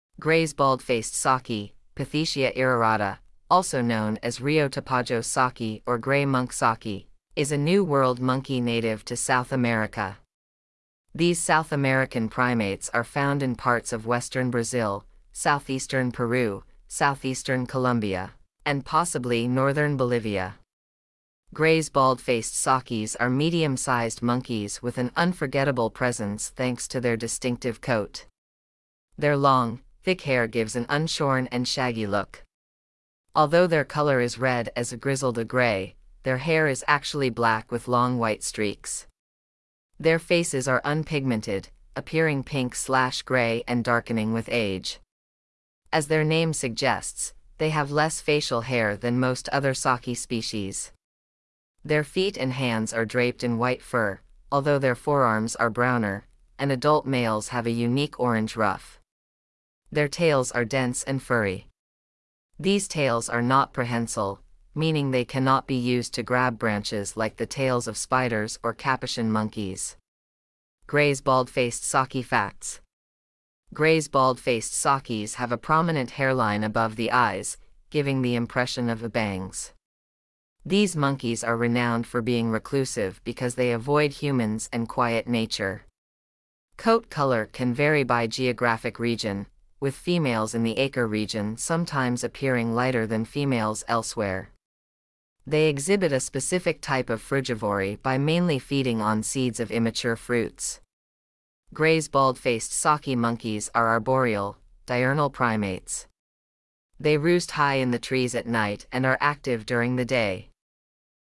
Gray’s Bald-faced Saki
Grays-Bald-faced-Saki.mp3